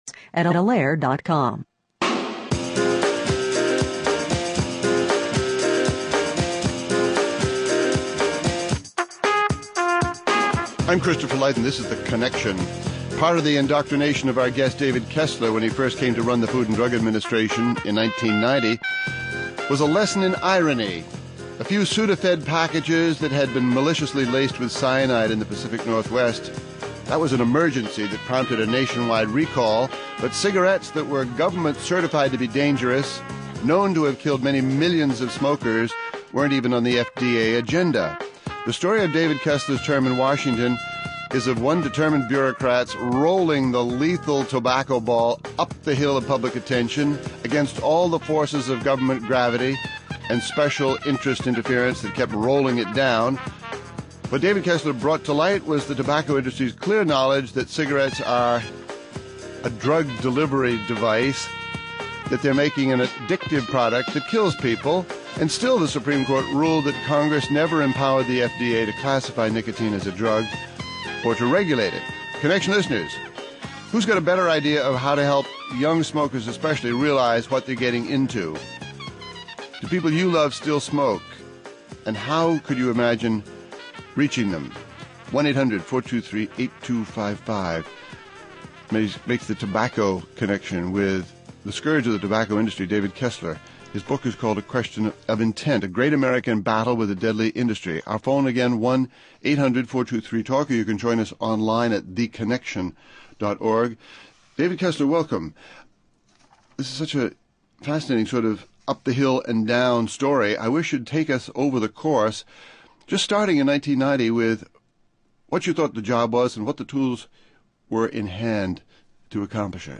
Join us for David Kessler’s wins and losses against Big Tobacco. (Hosted by Christopher Lydon)